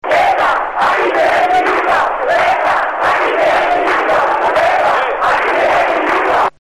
ETA aquí tienes mi nuca fue el grito en las calles